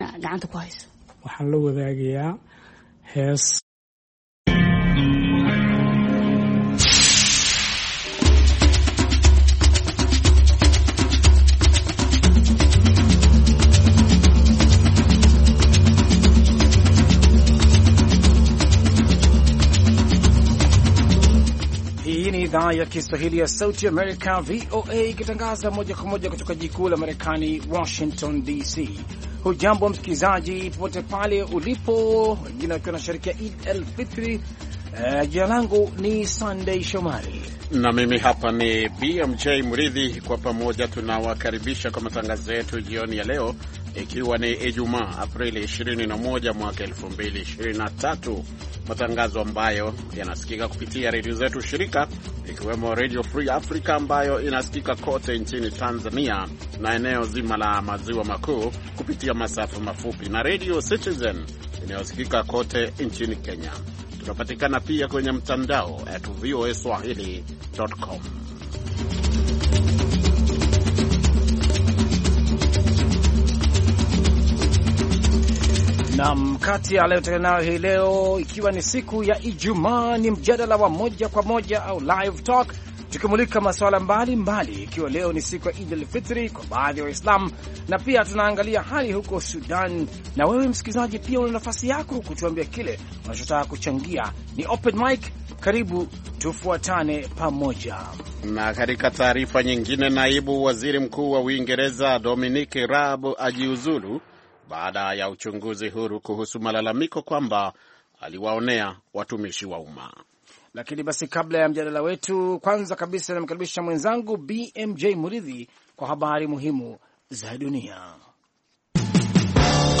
Katika mjadala wa moja kwa moja- Live talk tunamulika masuala mbali mbali ikiwa ni pamoja na vita vinavyoendelea Sudan, sherehe za Eid-El Fitr na pia maoni ya wasikilizaji